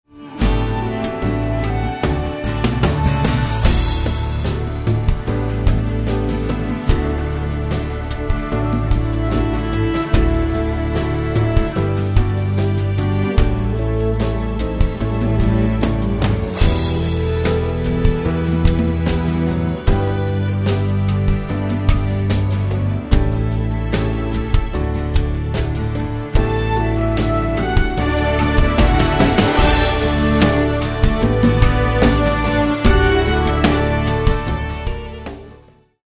performance track
orchestral , backing track
Instrumental